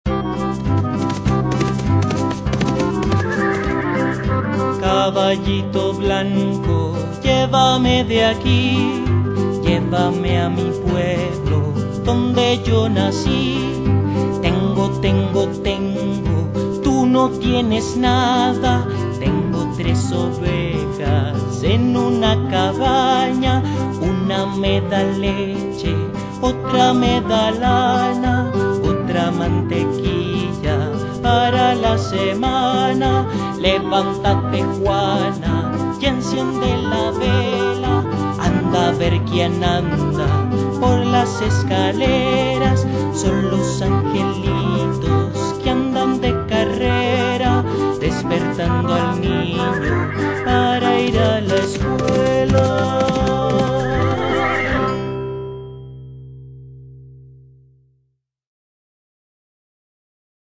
Música infantil
Canciones